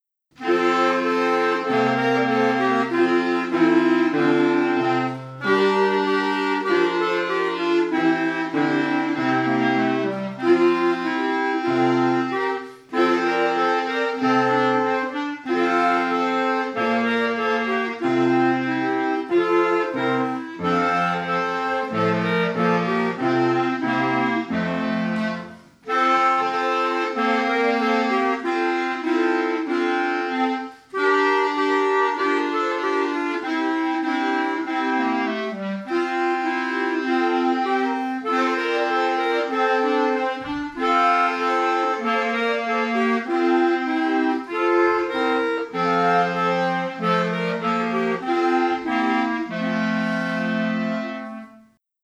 Das Vororchester hat seine Stücke aufgeführt und aufgenommen.